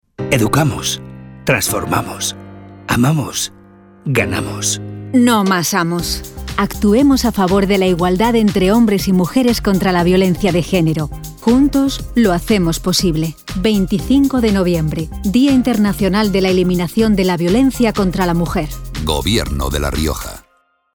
Descargas útiles Descargar la ficha básica de campaña Descargar el pliego de prescripciones técnicas del contrato de difusión publicitaria Elementos de campaña Publicidad Cartel Cuñas radiofónica Cuña de 20"